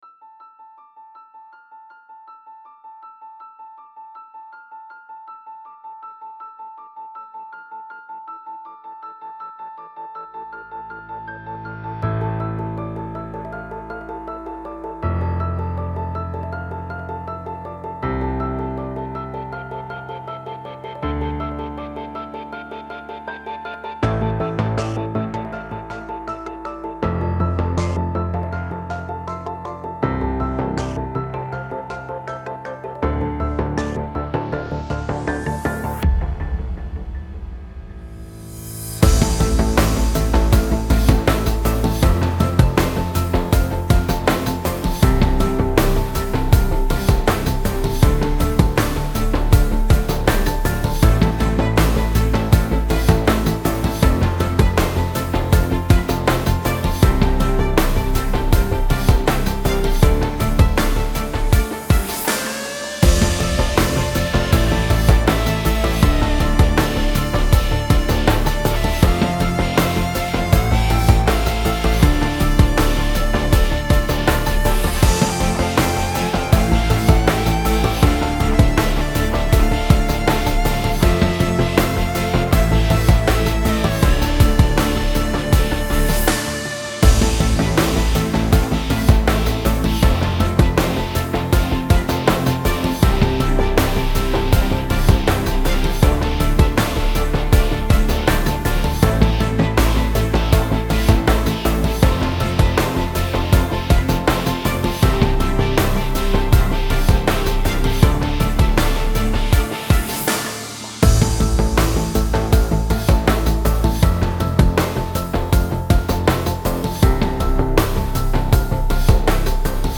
Немного синематики
В синемашном стиле.